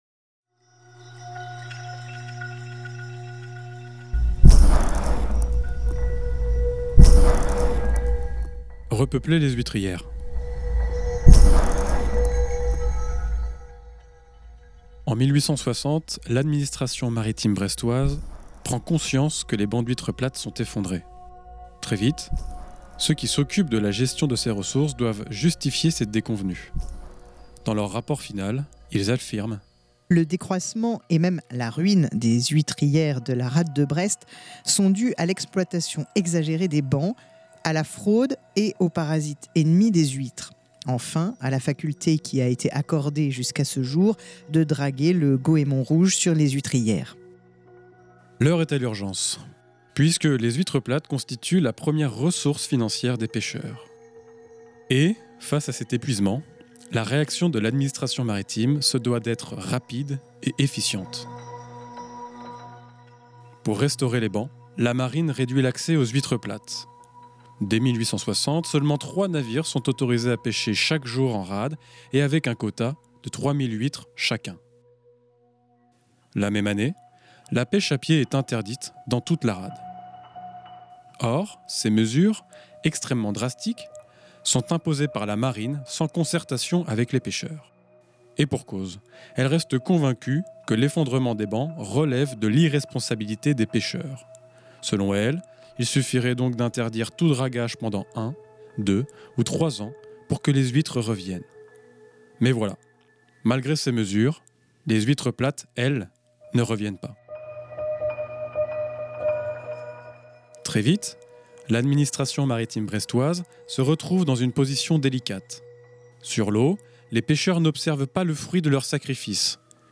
Voici donc le format radio de ce travail, une série de chroniques de quelques minutes à écouter une fois par semaine, le mercredi en début de Lem.